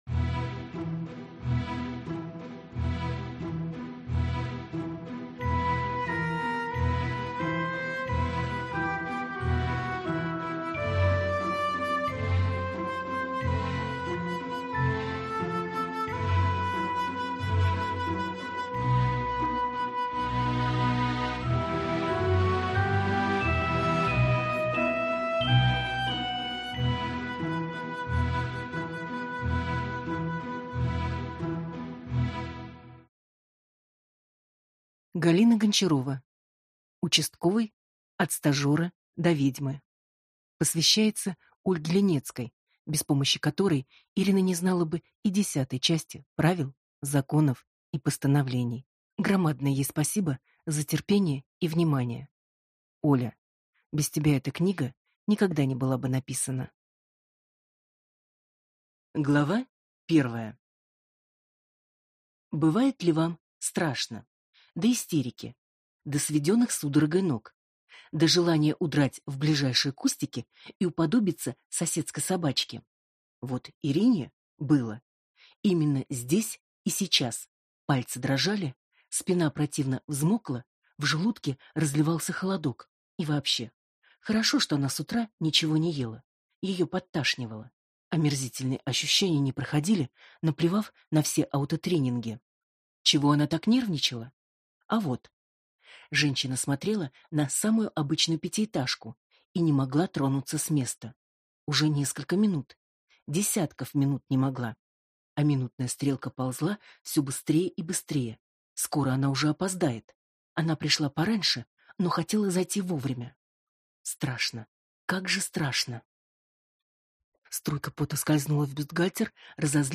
Аудиокнига Участковый. От стажера до ведьмы | Библиотека аудиокниг